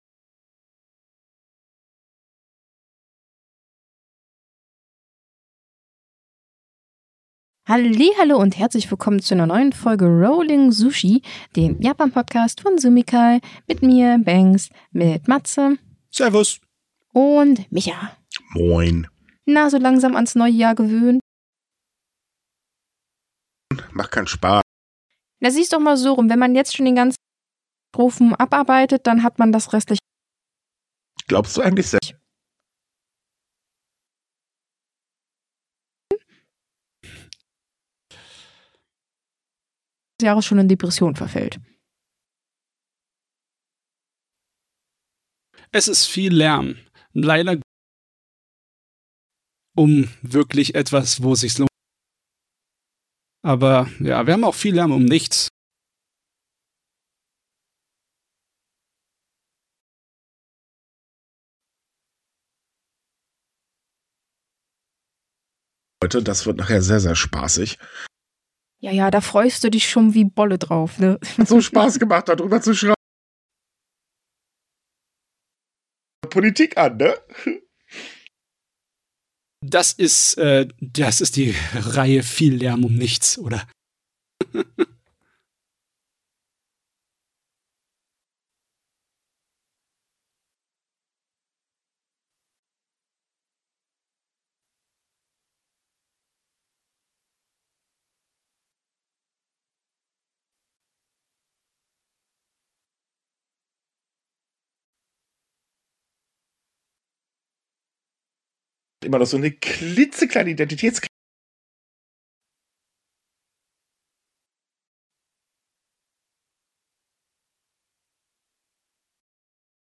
Mit dabei Redakteure von Sumikai und AnimeSlam.